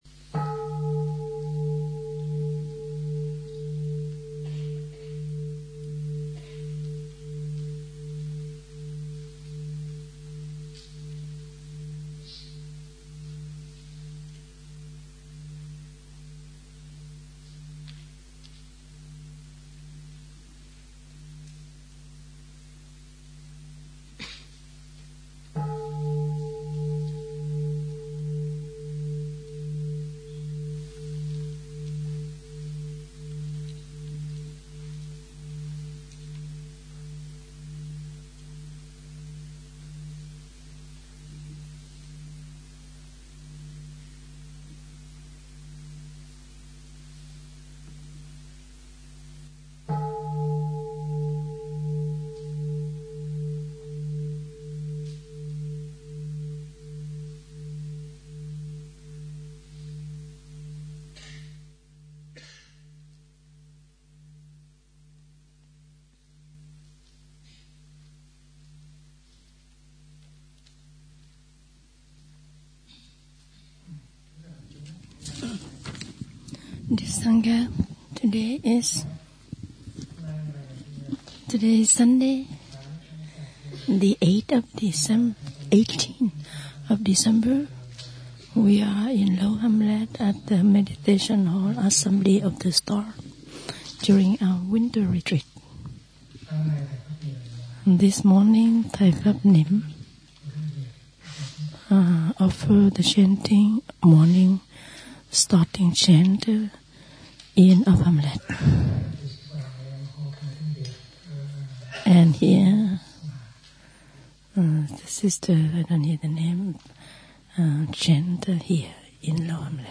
The talk is given in Vietnamese with English translation provided by Sr. Chan Khong. We learn of a new prayer that Thay has been working on for the past several months.